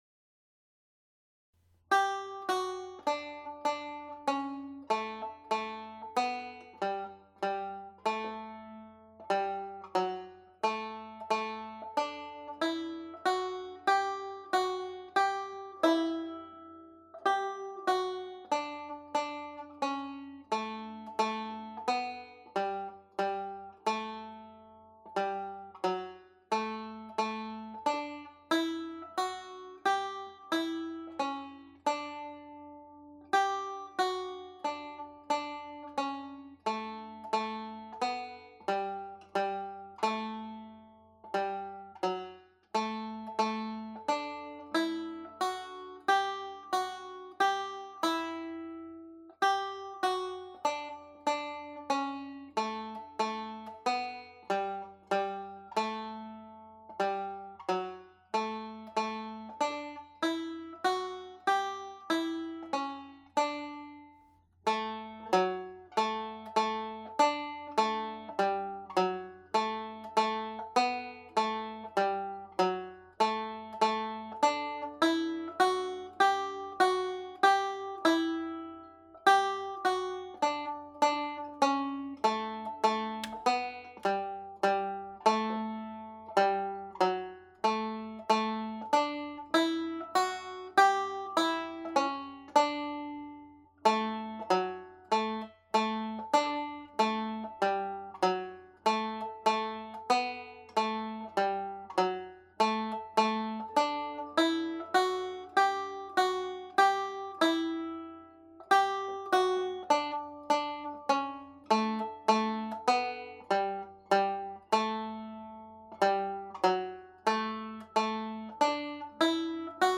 Pure Banjo » Intermediate Level
My Darling Asleep played slowly